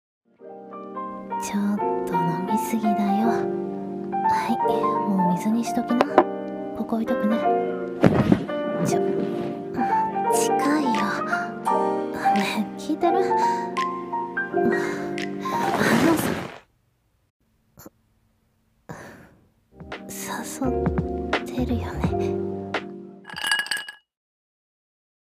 誘ってるよね 【シチュボ 1人声劇】